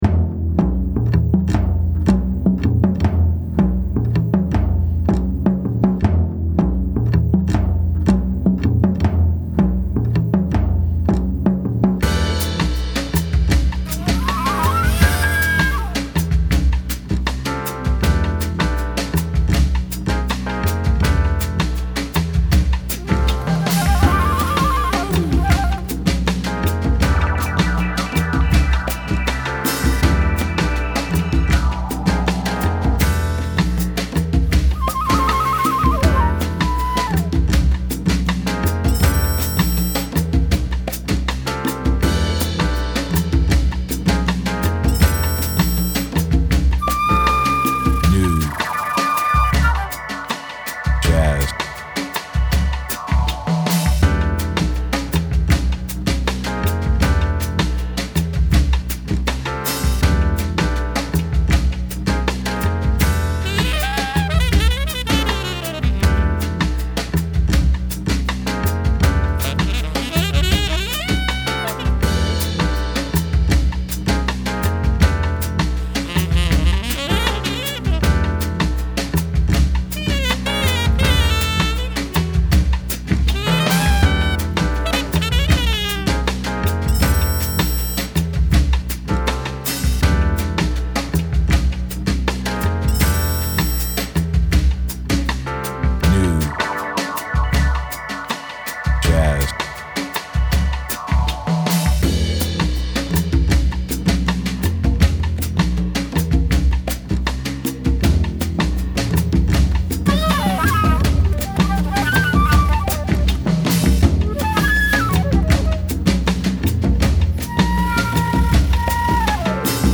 acompañamiento
piano
jazz
Sonidos: Música